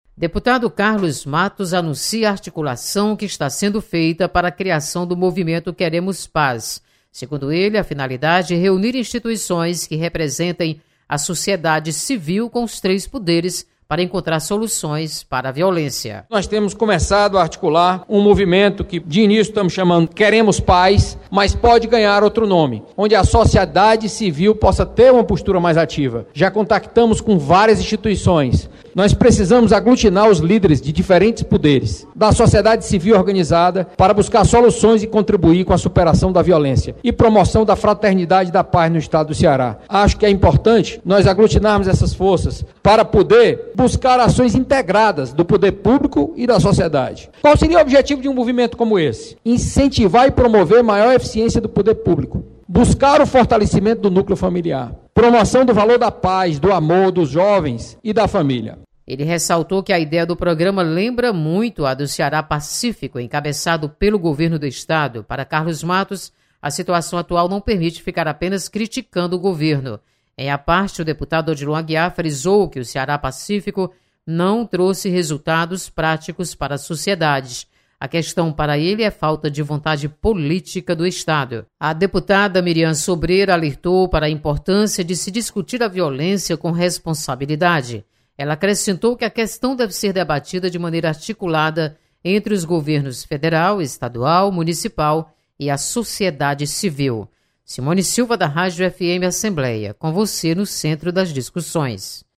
Deputado anuncia movimento Queremos Paz. Repórter